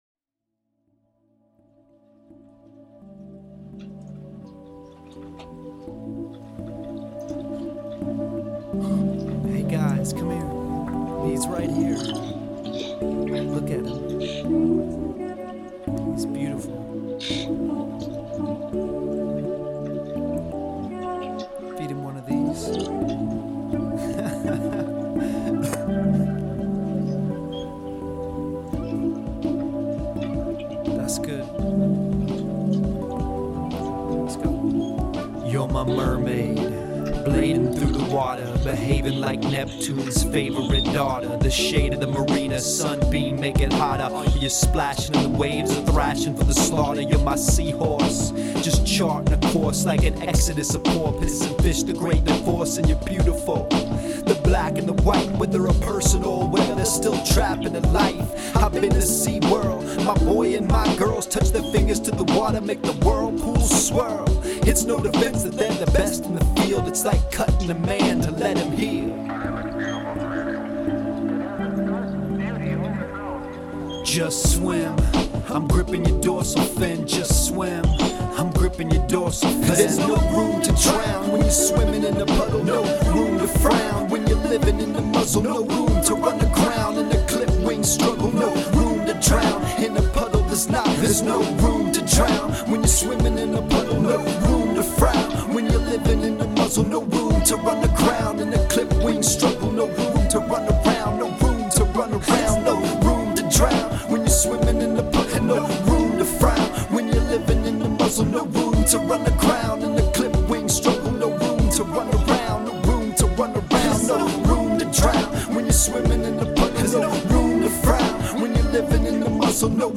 At exactly one, I am the world’s leading expert on Sea World Orca raps.